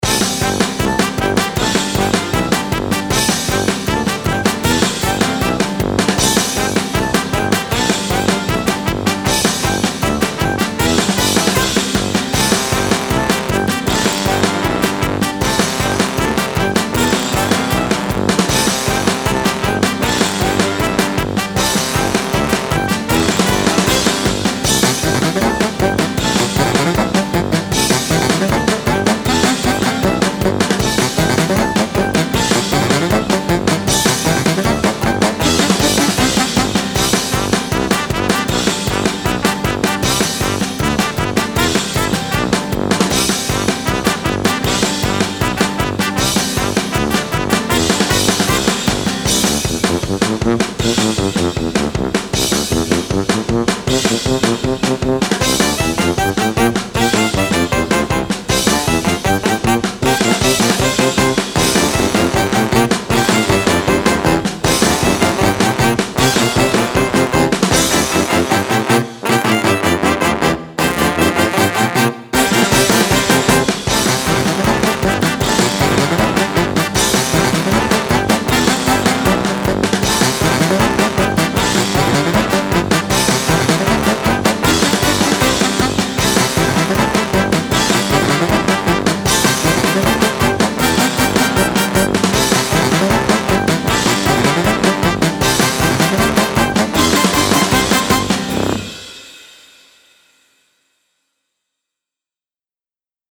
Style Style Folk, Other
Mood Mood Bright, Funny
Featured Featured Brass, Drums, Saxophone
BPM BPM 156